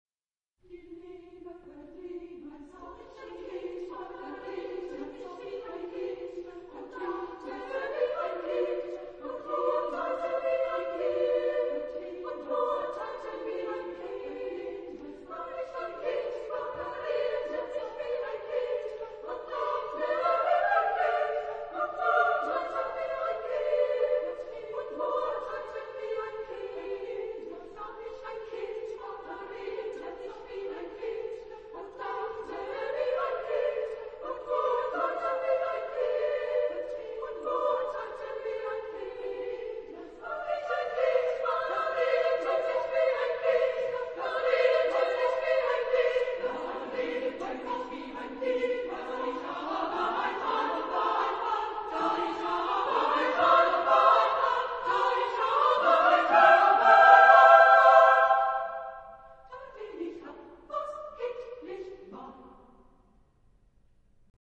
Genre-Style-Forme : Motet ; Cycle ; Sacré ; Profane
Type de choeur : SSSSAA  (6 voix égales de femmes )
Instruments : Clochettes de traîneau ; Claves (1)
Tonalité : libre